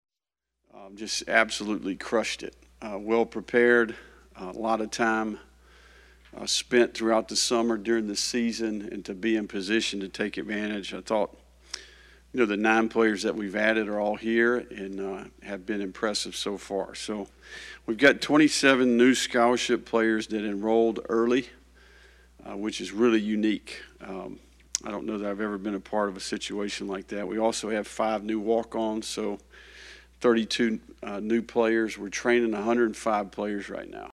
Napier detailed where Florida stands to the media during Wednesday’s press conference.